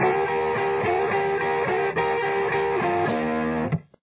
Guitar 3
Here’s the bluesy fill for the fourth measure: